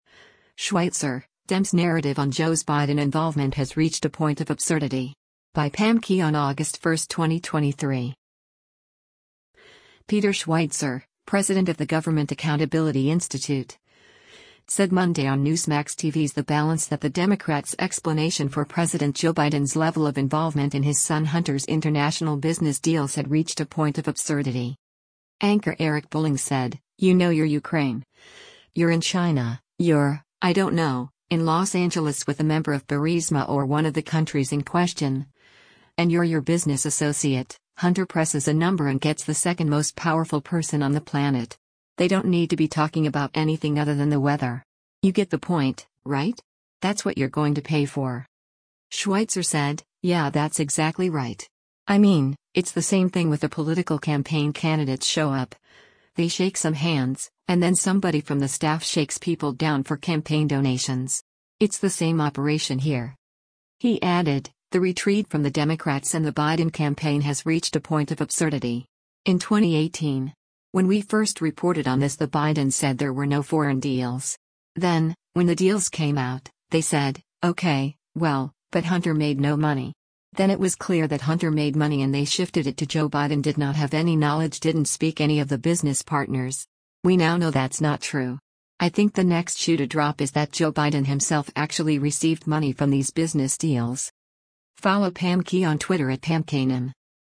Peter Schweizer, president of the Government Accountability Institute, said Monday on Newsmax TV’s “The Balance” that the Democrats’ explanation for President Joe Biden’s level of involvement in his son Hunter’s international business deals had “reached a point of absurdity.”